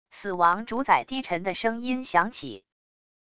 死亡主宰低沉的声音响起wav音频